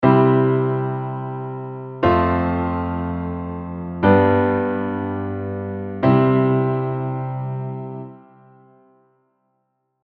We’ll start with the most basic progression with these three chords, I IV V I. Notice the increase in tension and then the release from the G to the C in bar 4.